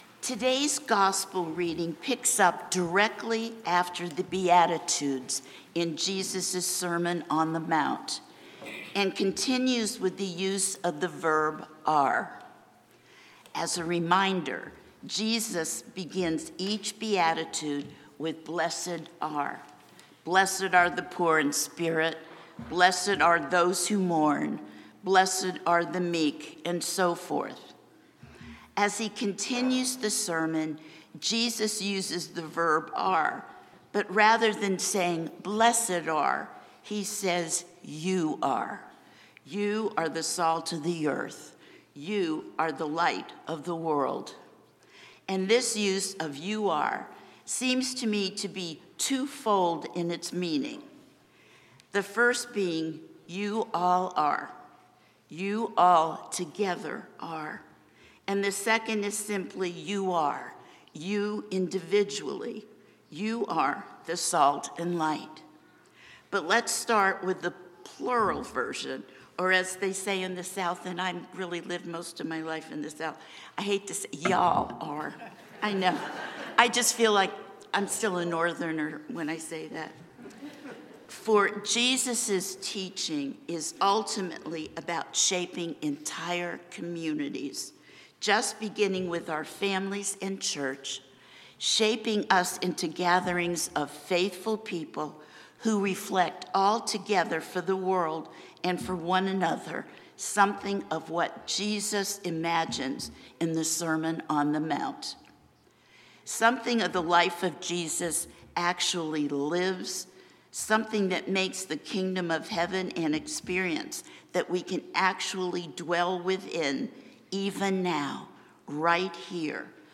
St-Pauls-HEII-9a-Homily-08FEB26.mp3